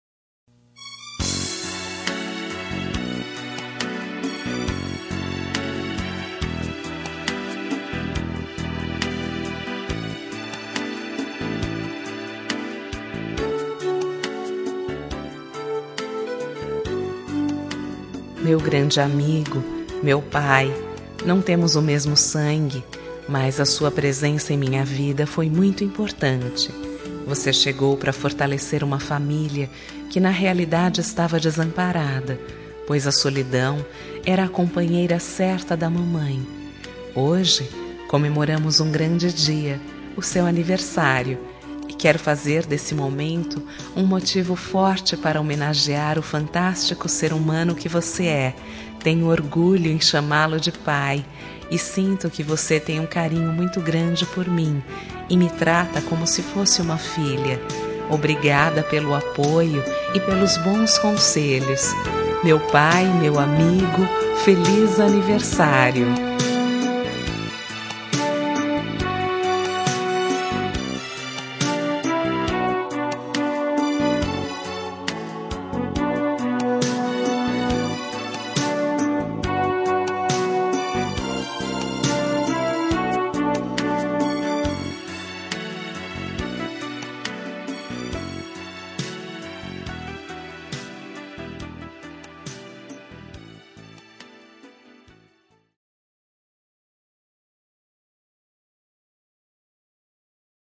Telemensagem de Aniversário de Pai – Voz Feminina – Cód: 1481 Padrasto